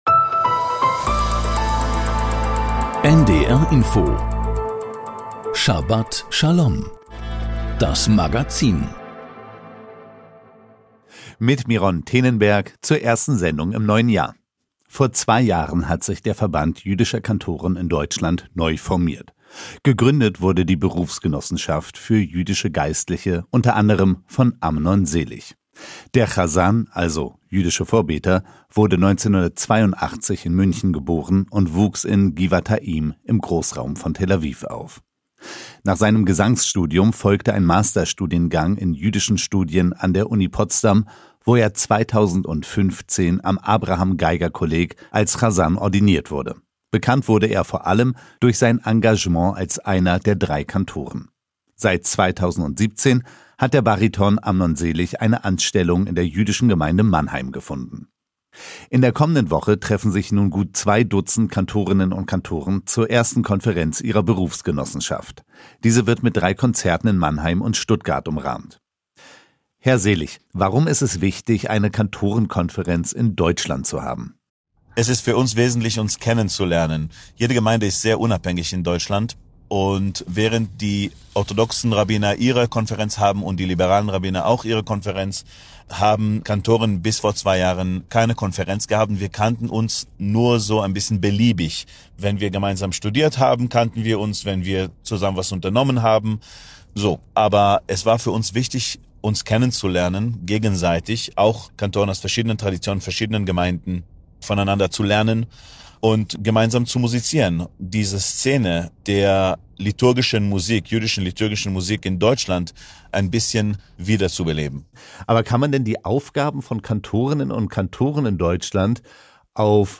Das Magazin Schabat Schalom berichtet aus dem jüdischen Leben mit Nachrichten, Interviews, Berichten und Kommentaren. Dazu die Wochenabschnittsauslegung der Thora.